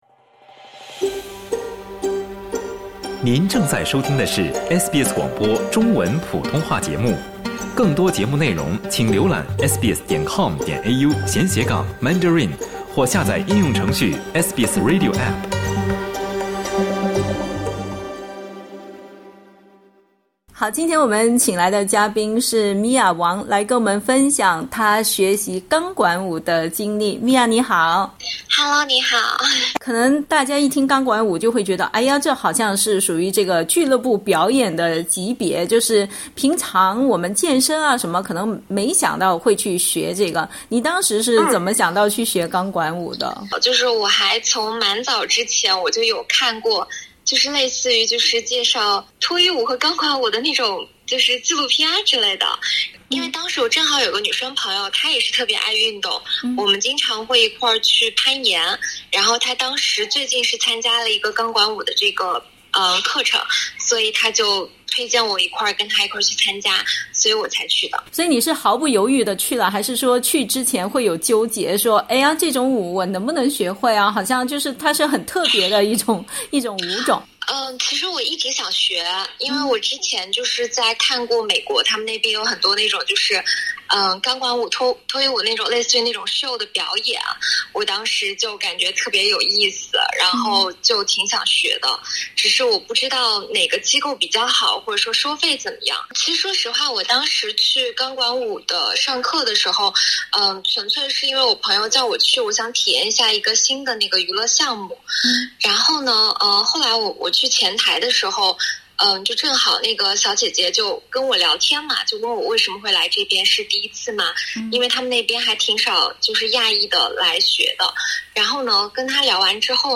（点击图片收听完整采访） 钢管舞（Pole dance）是一种将舞蹈和体操相结合的表演艺术。